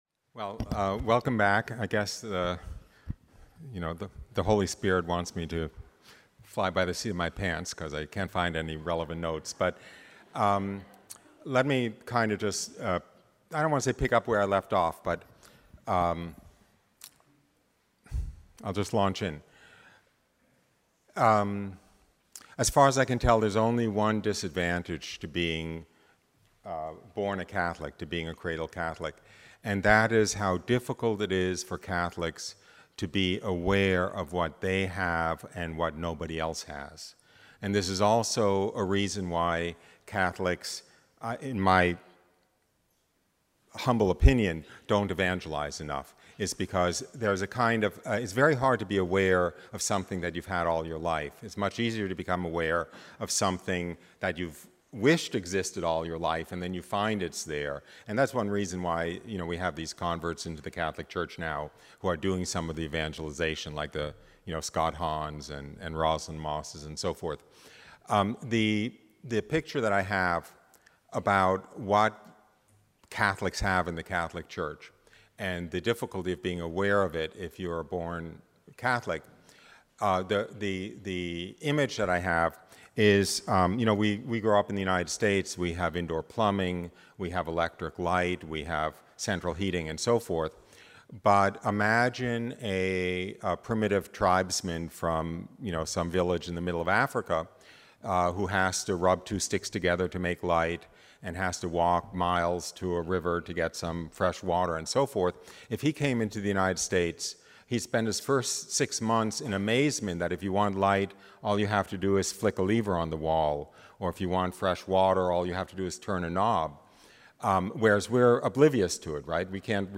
Spring Marian Day 2019- CONF 481